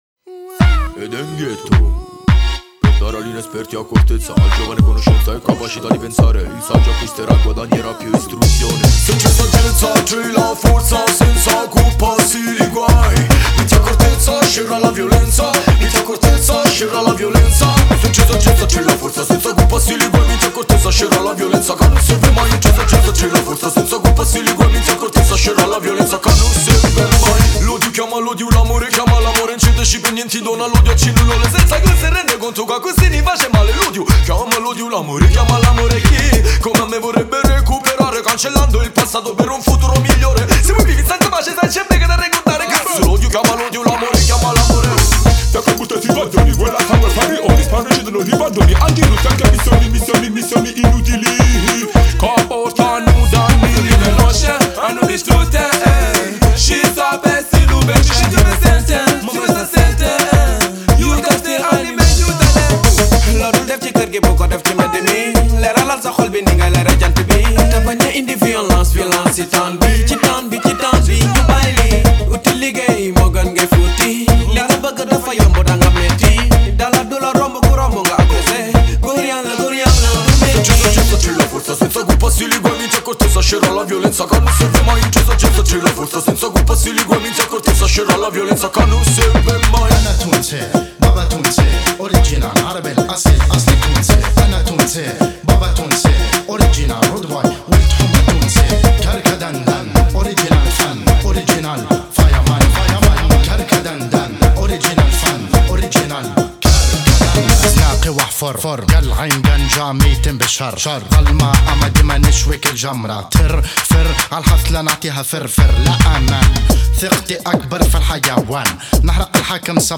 X'XX' riddim